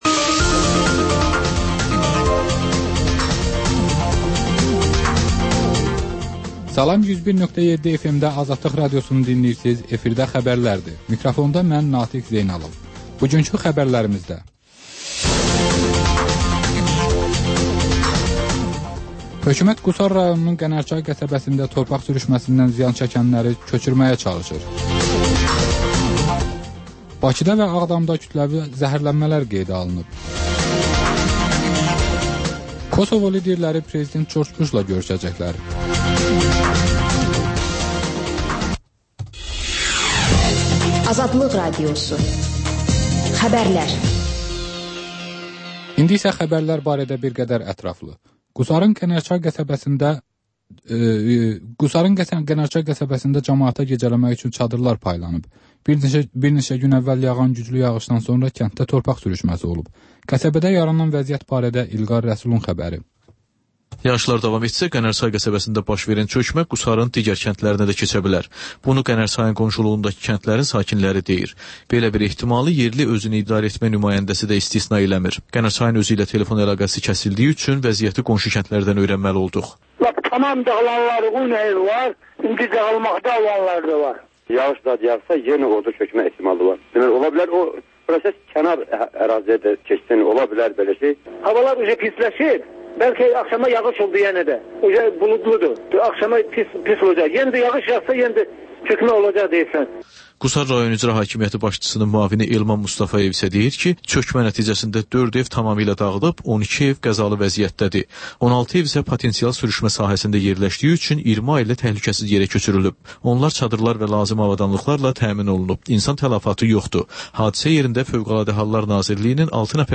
Xəbərlər, müsahibələr, hadisələrin müzakirəsi, təhlillər, sonda isə HƏMYERLİ rubrikası: Xaricdə yaşayan azərbaycanlıların həyatı